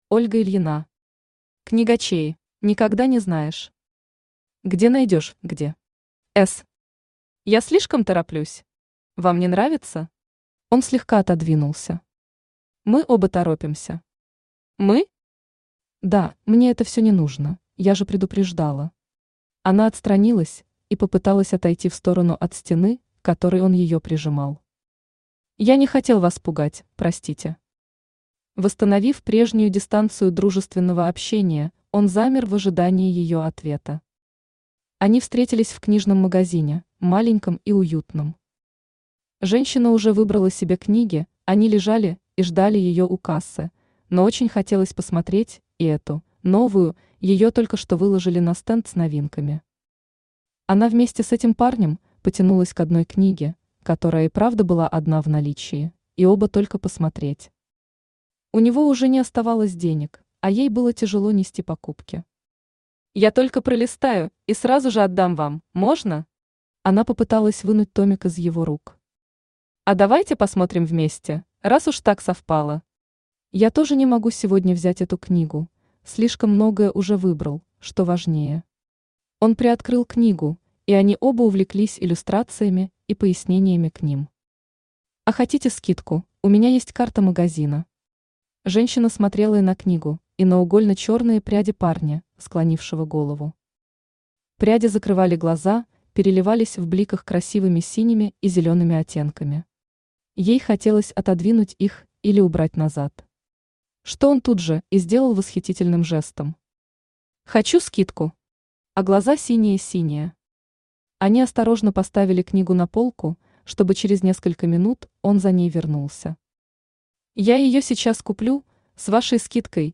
Аудиокнига Книгочеи | Библиотека аудиокниг
Aудиокнига Книгочеи Автор Ольга Тигра Ильина Читает аудиокнигу Авточтец ЛитРес.